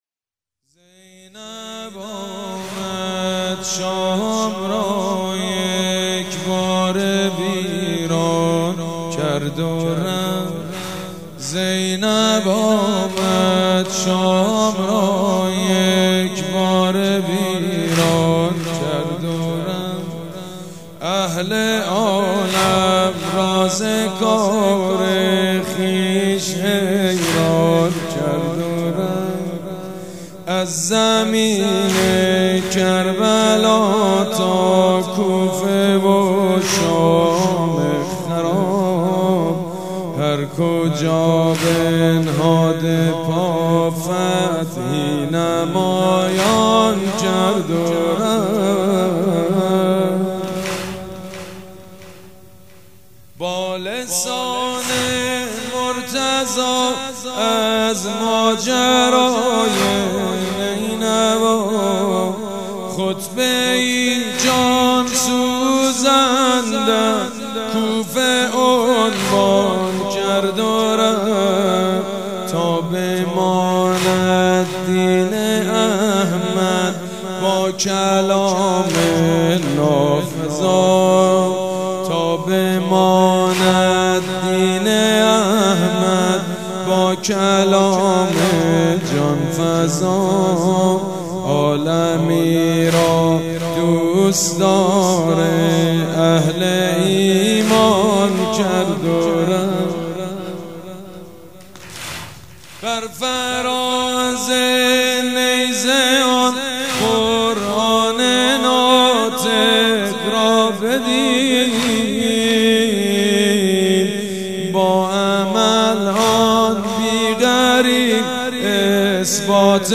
مراسم شب اول ماه صفر | یکشنبه ۳۰ مهرماه ۱۳۹۶
مداح
مراسم عزاداری شب اول